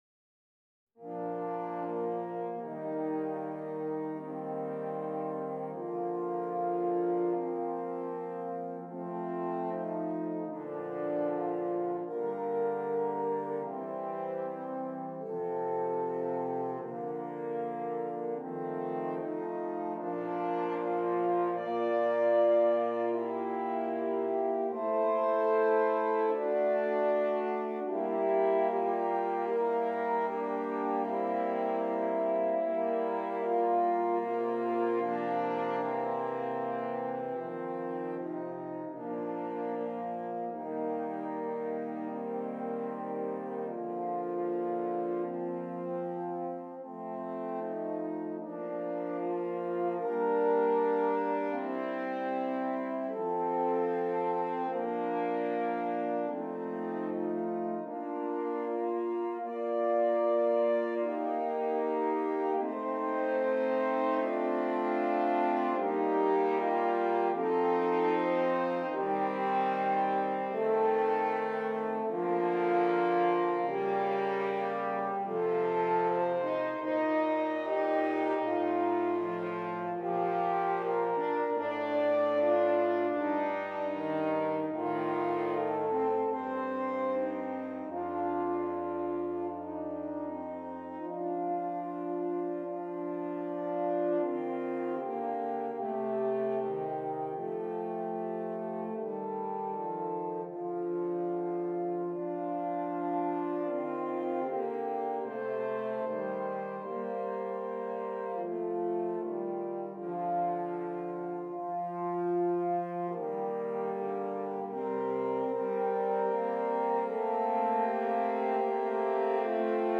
4 F Horns